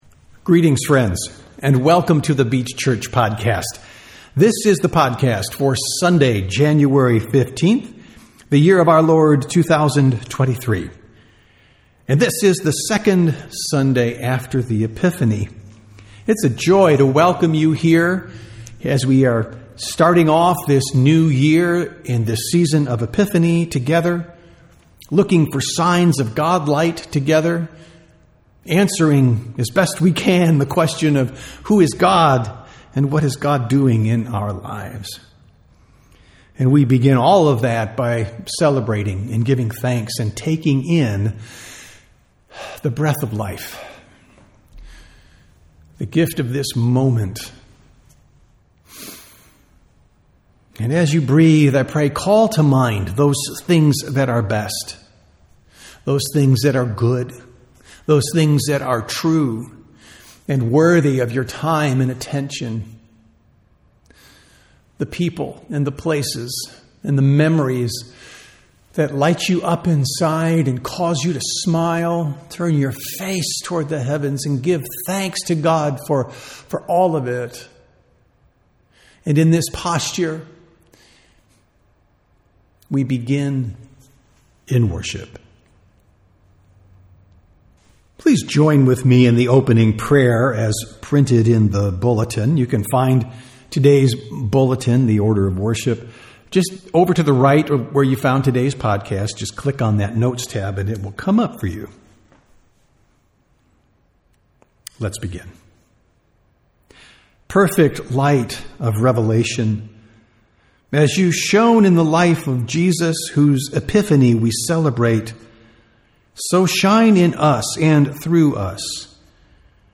Sermons | The Beach Church
Sunday Worship - January 15, 2023